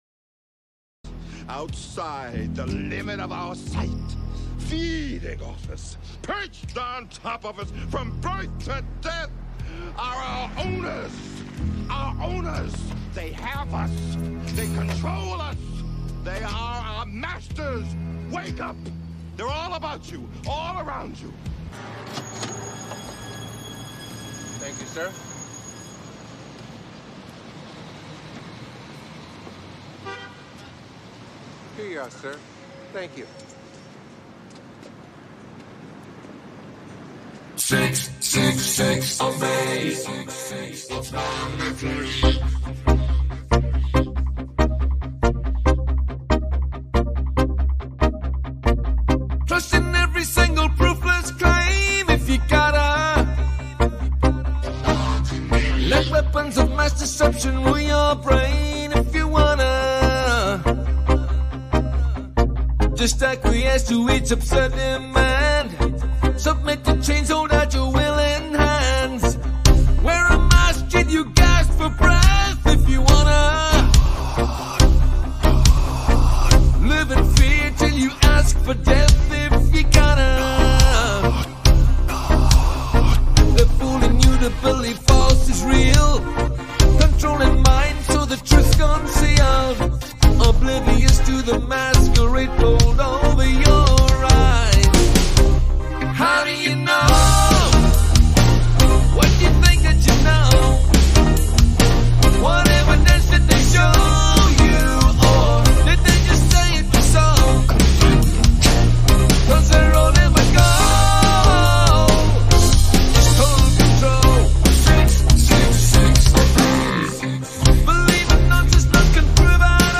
Catchy Meaningful Memorable Melodic Rock.